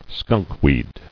[skunk·weed]